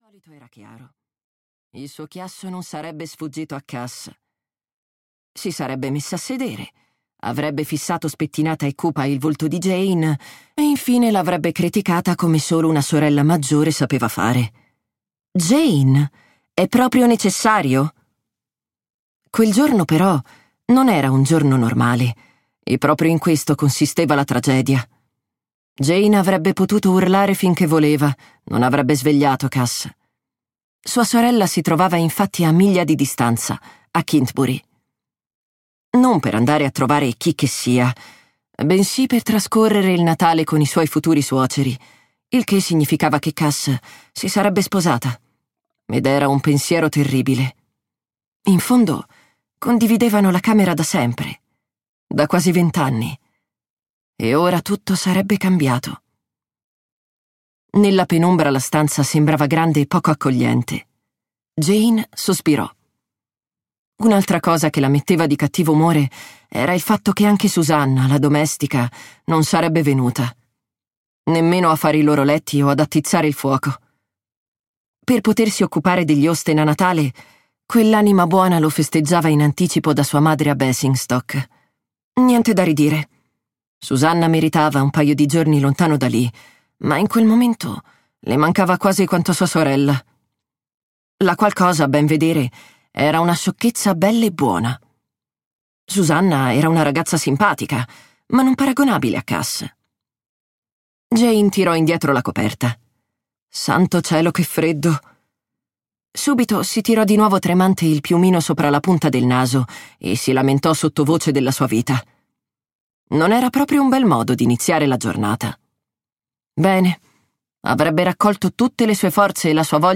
"Un tè con Jane Austen" di Catherine Bell - Audiolibro digitale - AUDIOLIBRI LIQUIDI - Il Libraio